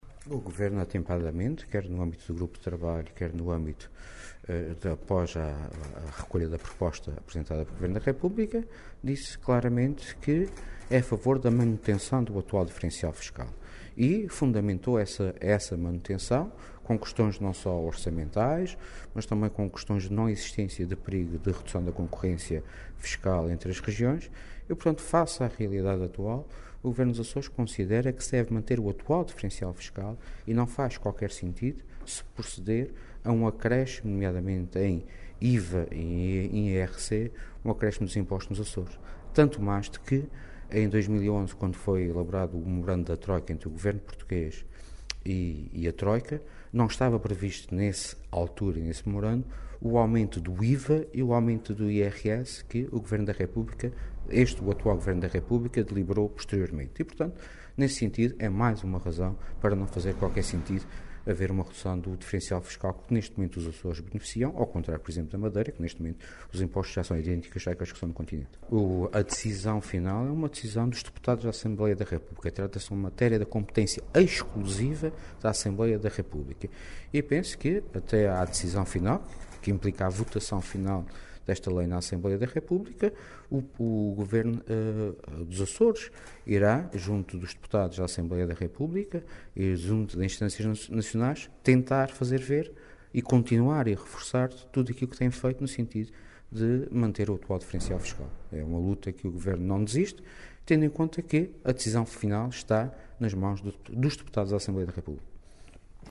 A defesa da manutenção do diferencial fiscal entre os Açores e o resto do país foi reiterada pelo Vice-Presidente do Governo Regional, Sérgio Ávila, em declarações à margem das audições aos partidos políticos e parceiros sociais sobre as propostas de Orientações de Médio Prazo 2013-2016 e de Plano Anual Regional para 2013, que decorrem hoje no Palácio da Conceição.